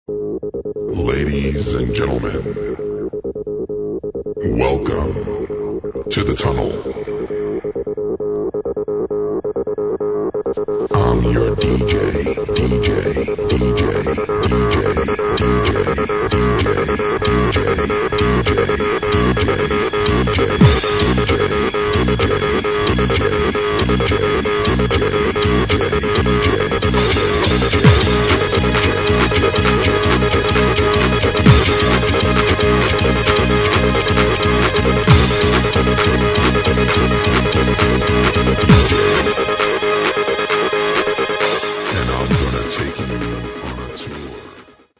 ID Classic Acid Track New Remix or bootleg